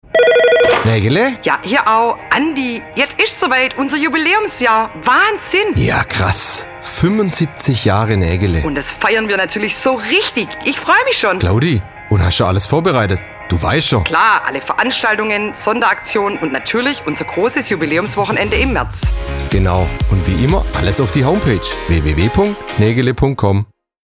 Radiowerbung: 75 Jahre Negele
Presse-Radiospot-75-Jahre-Negele.mp3